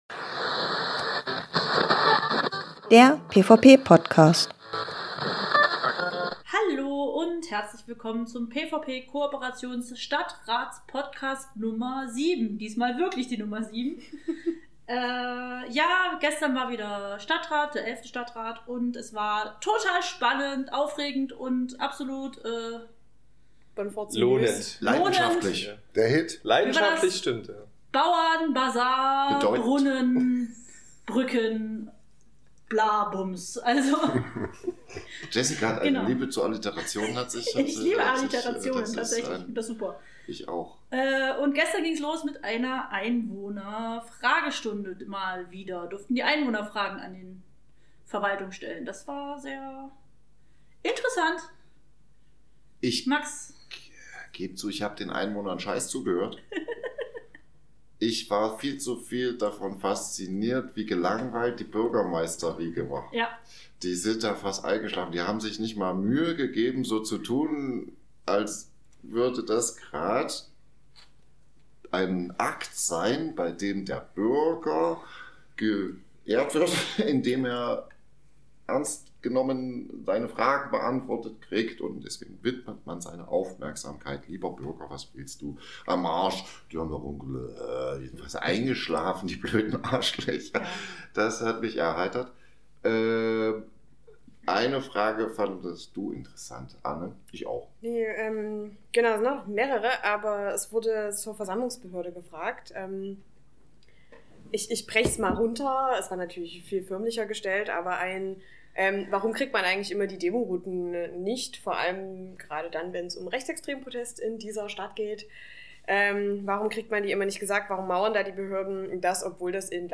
Jessica Roitzsch, Martin Schulte-Wissermann, Anne Herpertz, Max Aschenbach und Manuel Wolf im Gespräch über die Stadtratssitzung vom 22.05.2025. Findet heraus, wie aus simpler Bürokratie ein Grundsatzkampf um Gleichstellung wird, die Carolabrücke zur tragenden Säule für die Dresdner Wirtschaft wird...